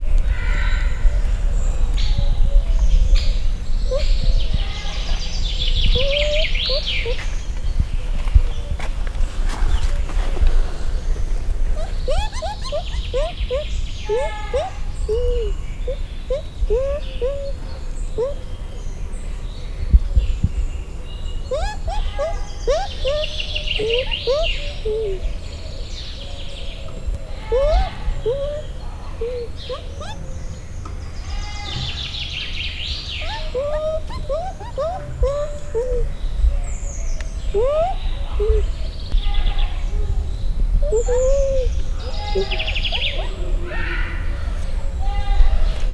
erdeikutya00.45.wav